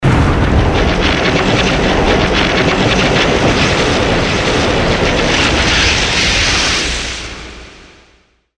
Root > sounds > weapons > hero > disruptor
static_storm.mp3